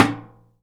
metal_tin_impacts_soft_06.wav